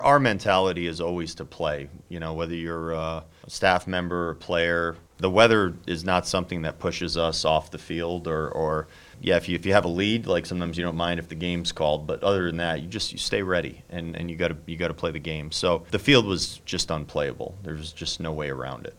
Twins manager Rocco Baldelli says they all worked hard to make sure this game got in until they couldn’t.